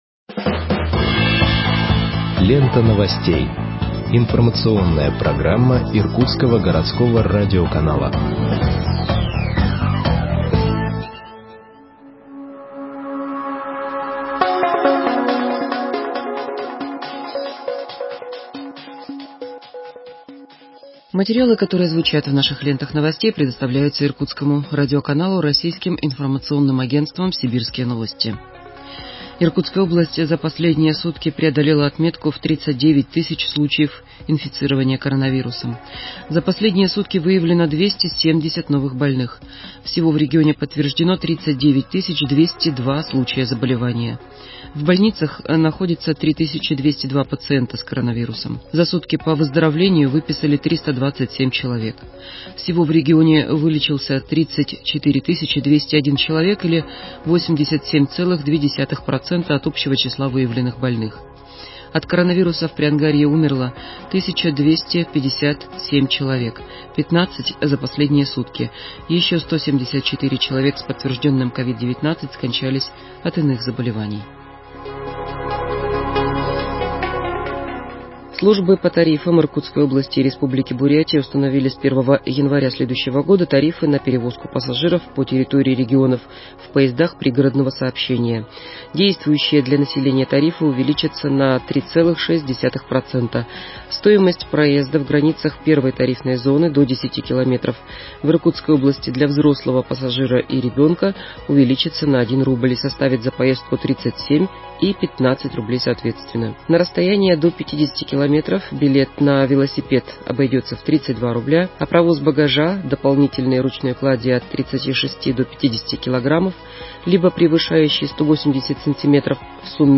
Выпуск новостей в подкастах газеты Иркутск от 25.12.2020